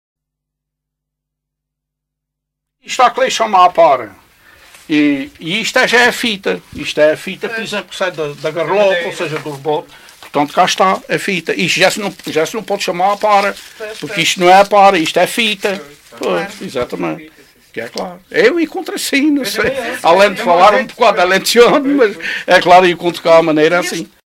LocalidadeCastelo de Vide (Castelo de Vide, Portalegre)